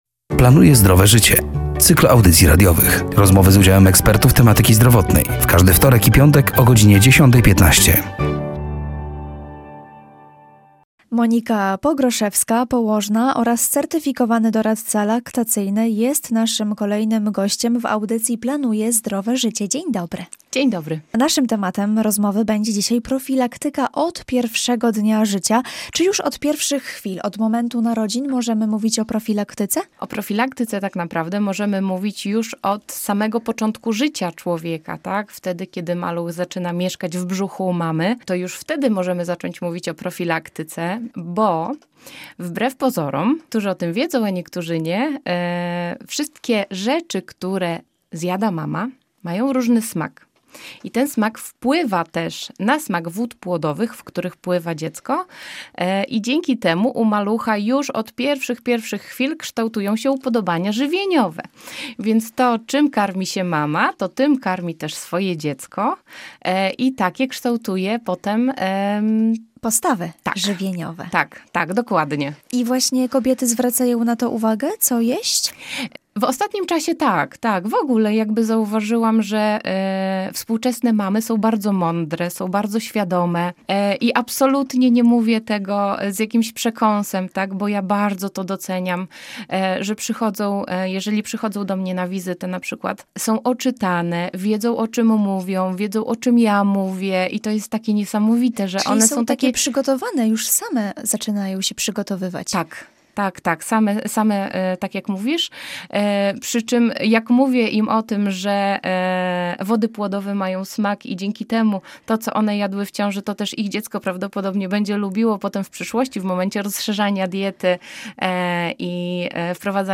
Rozmowy z udziałem ekspertów tematyki zdrowotnej.